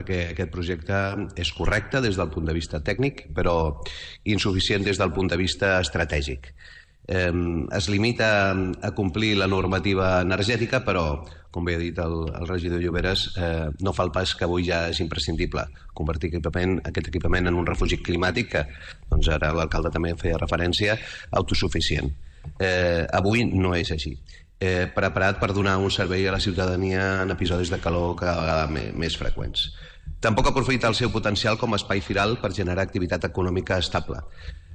Són declaracions de Toni Carrión.